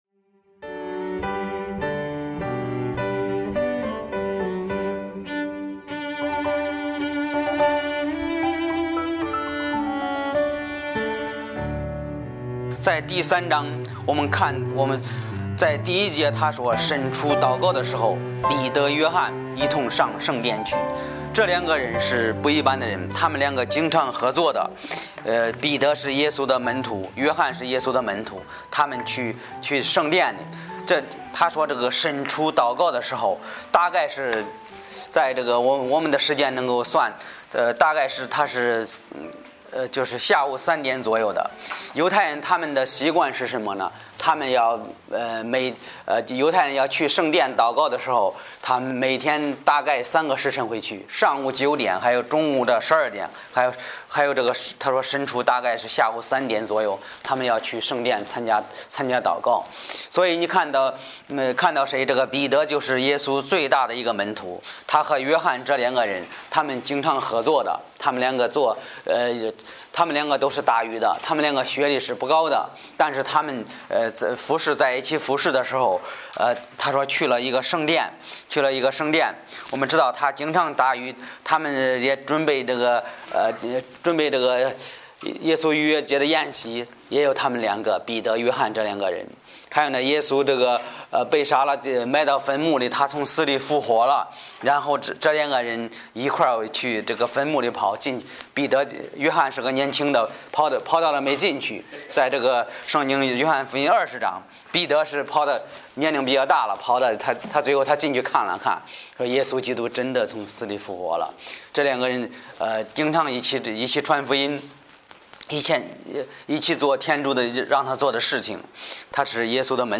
Bible Text: 使徒行传3：1-11 | 讲道者